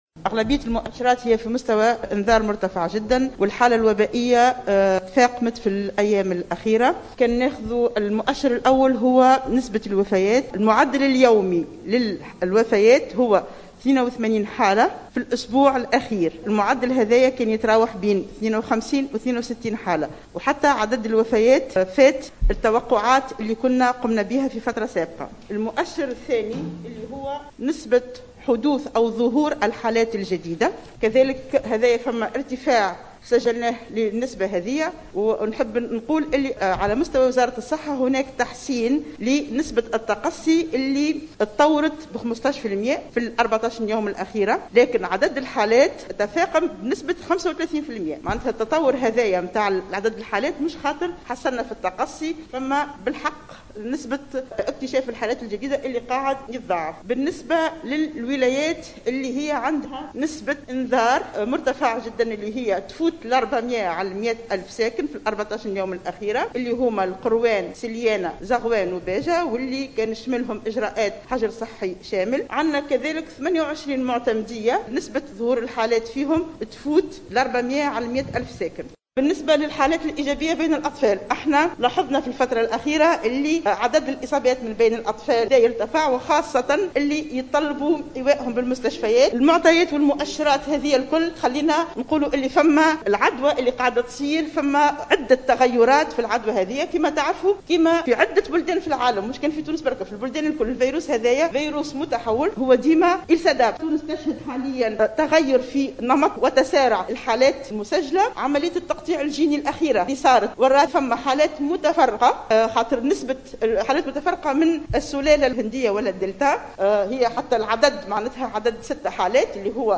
وأرجعت بن علية ذلك، خلال ندوة صحفية عقدتها بعد ظهر اليوم بقصر الحكومة بالقصبة، إلى تطور الفيروس، ووجود عدّة تغيّرات في العدوى ، مشيرة إلى أن تونس تشهد تغيّر في نمط وتسارع الحالات المسجلة.